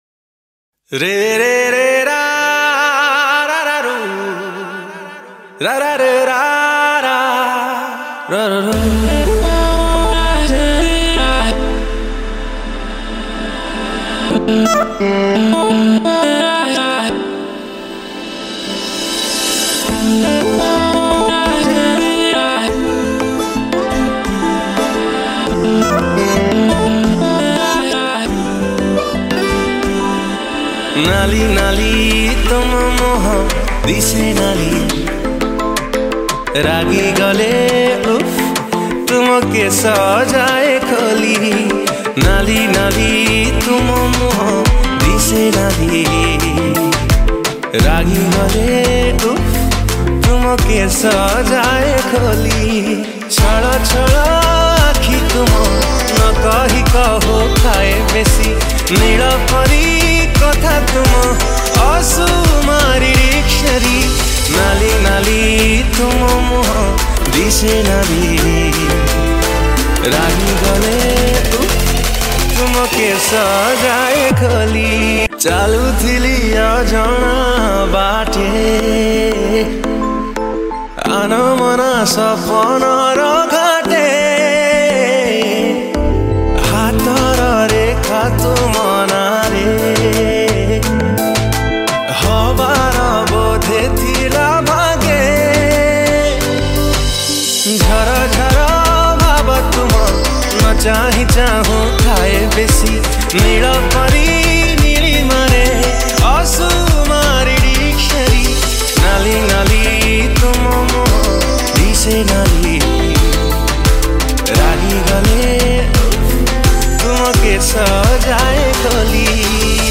Romantic Odia Song